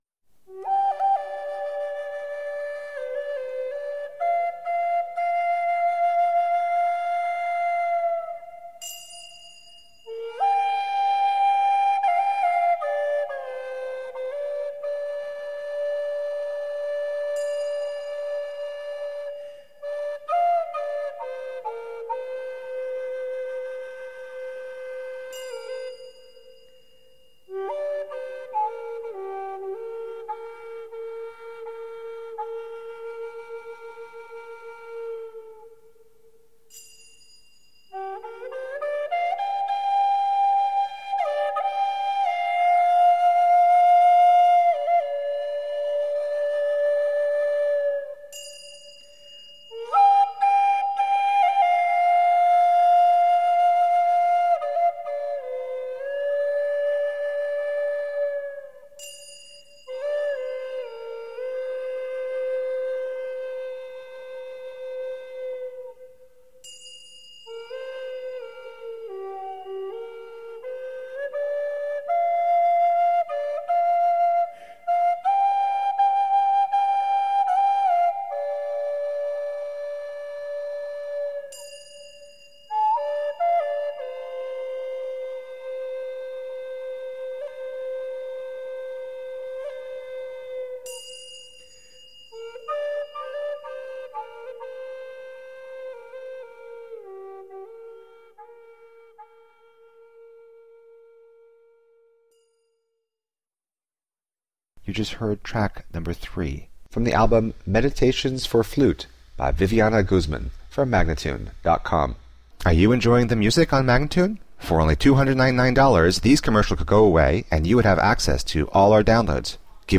Virtuosic flutist.
Soothing and luminescent soliloquies for flute.
Tagged as: World, New Age, Instrumental, Flute, Massage